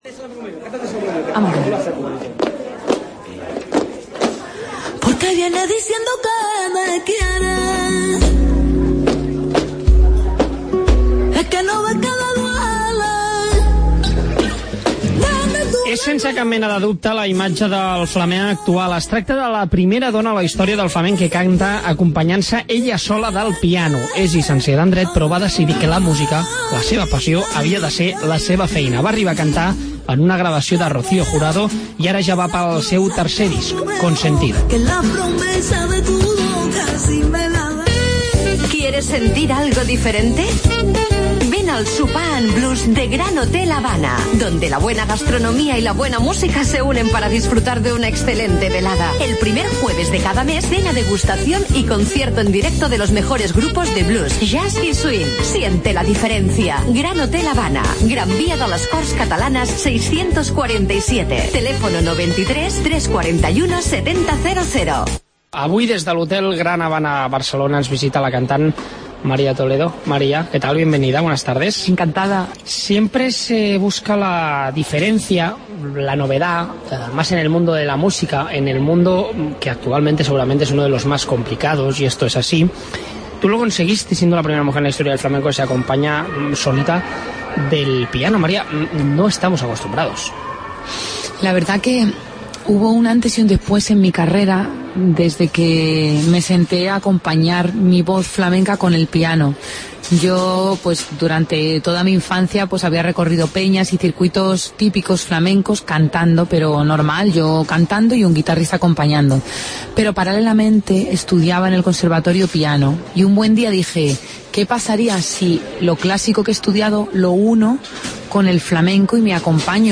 La cantant de flamenc Maria Toledo, nominada a 2 latin grammy's, ens ha visitat a La Tarda